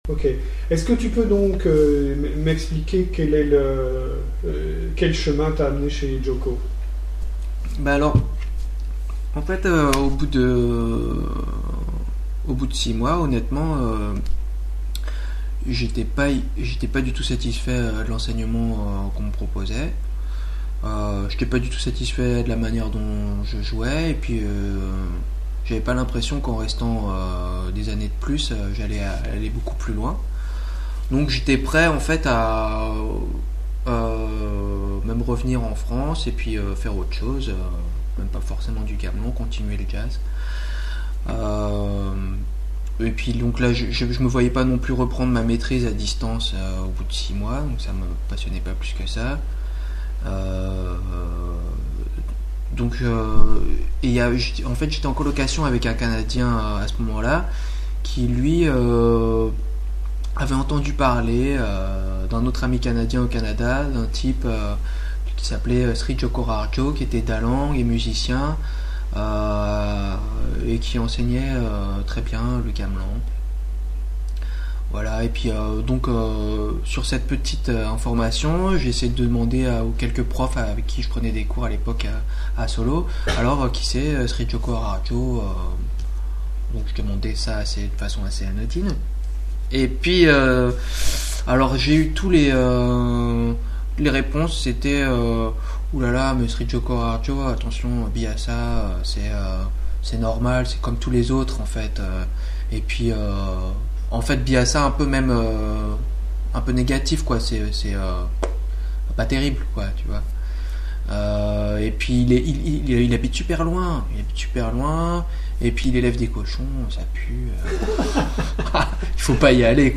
Extrait de l'interview